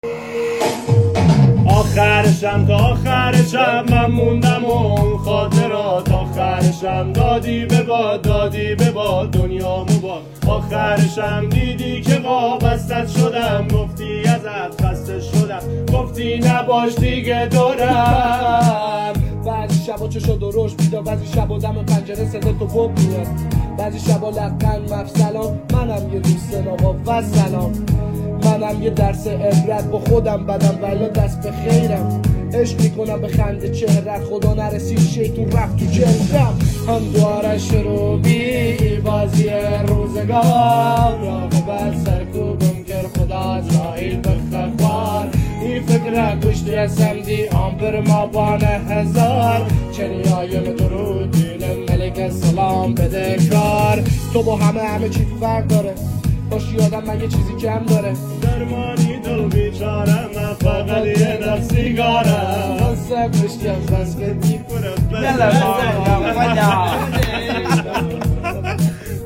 Iranian music